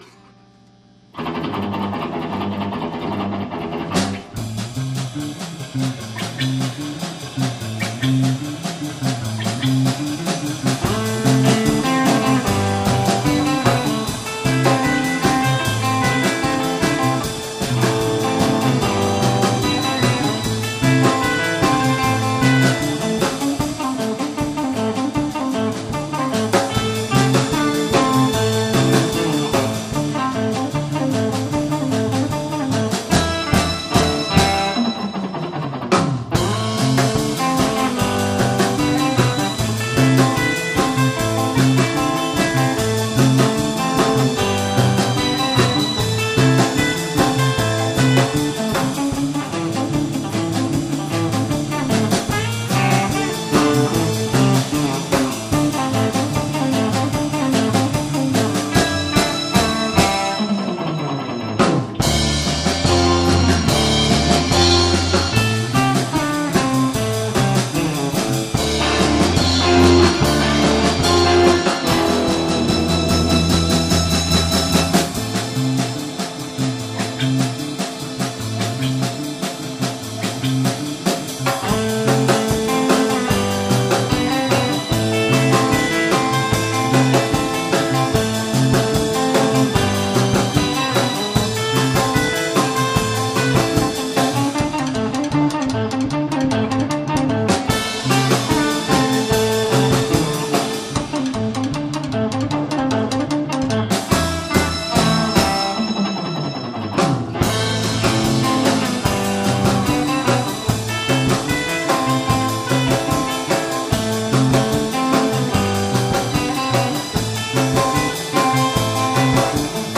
８月１２日（土）「ベンチャーズハウス六絃」にて「２０１７　Summer　Ｌｉｖｅ」を開催しました、